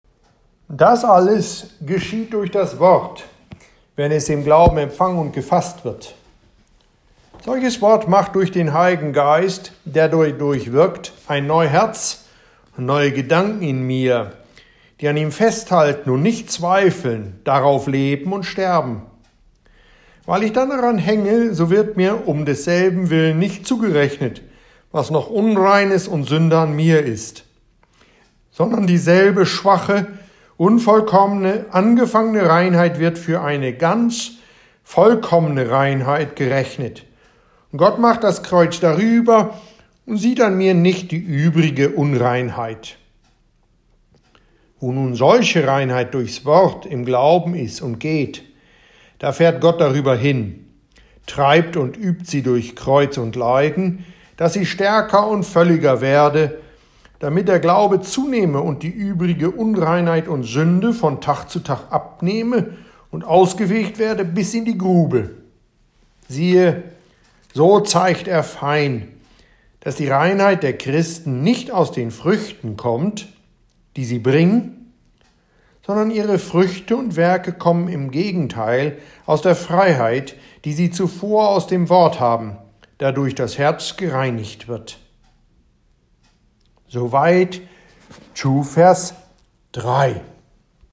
Dr. Martin Luther comments this vers – and here´s some of that audible in German – readings of John 15,3 in “Luthers Evangelien-Auslegung 4.Teil.” ed. Eduard Ellwein.